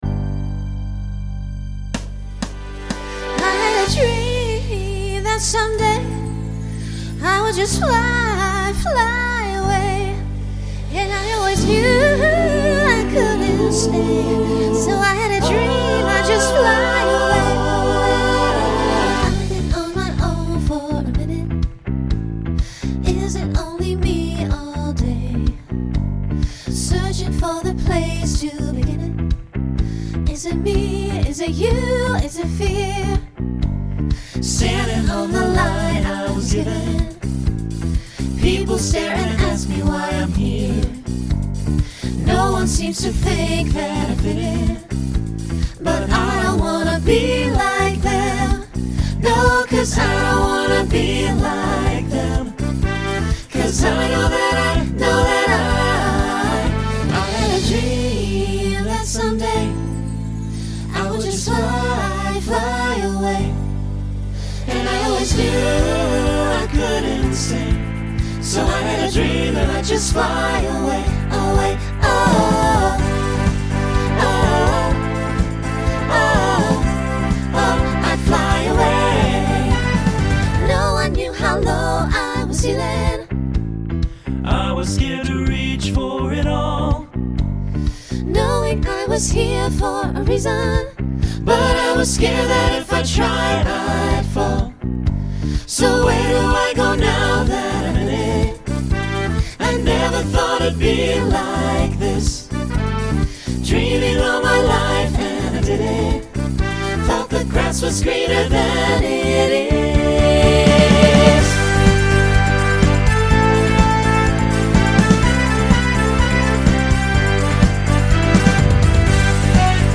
Pop/Dance
Voicing SATB